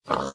pig_say1.ogg